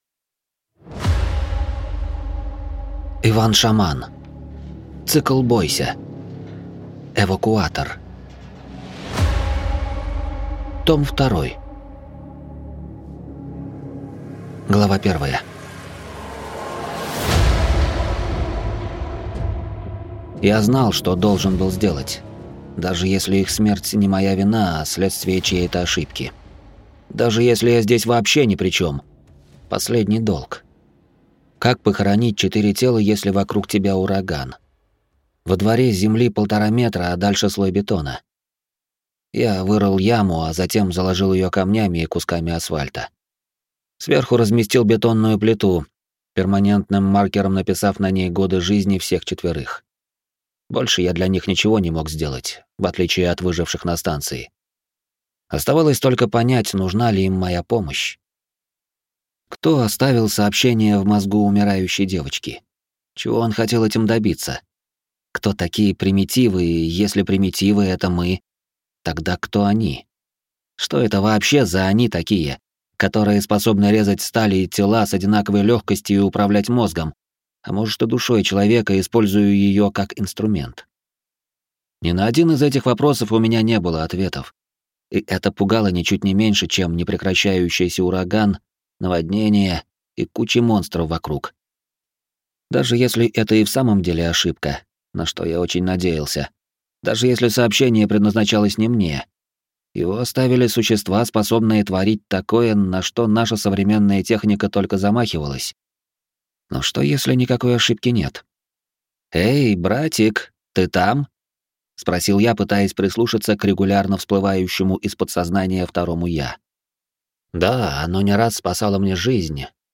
Аудиокнига Эвакуатор. Книга вторая | Библиотека аудиокниг